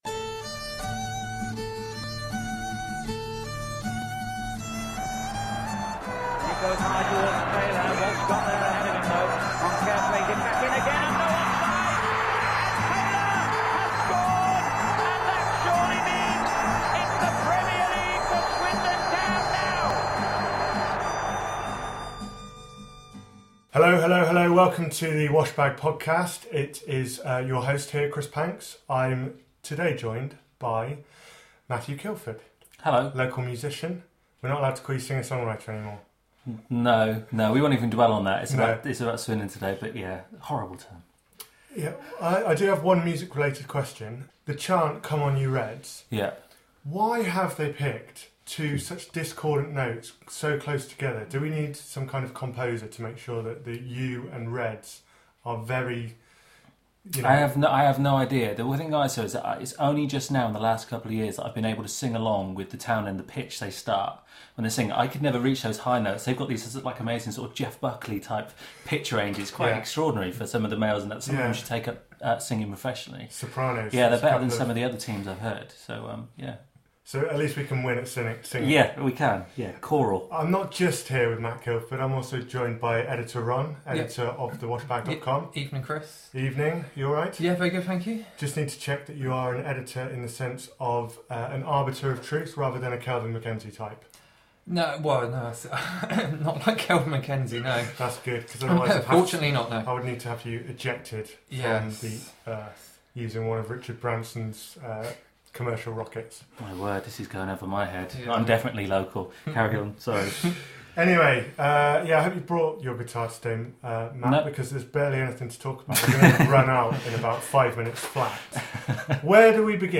The three review the recent spate of defeats, Di Canio’s attitude and response to player performances, the deadline day signings and a look ahead to the games with Carlisle (yes this was recorded on Friday evening) and a visit to Portsmouth.